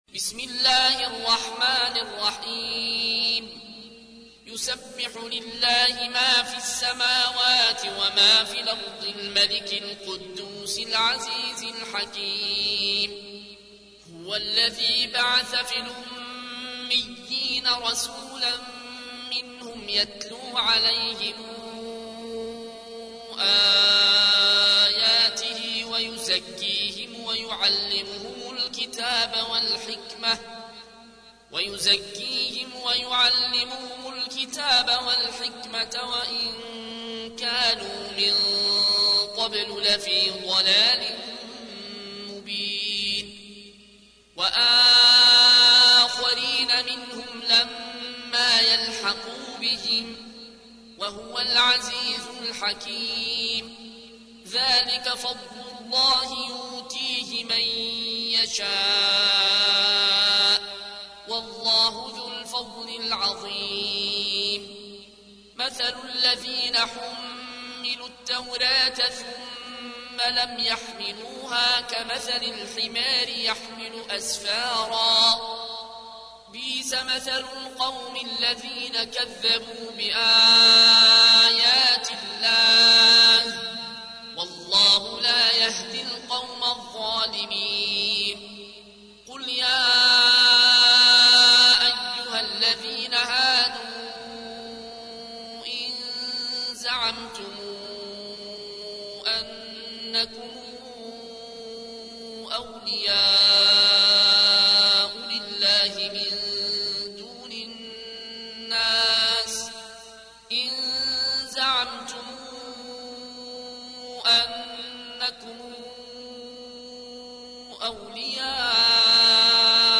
تحميل : 62. سورة الجمعة / القارئ العيون الكوشي / القرآن الكريم / موقع يا حسين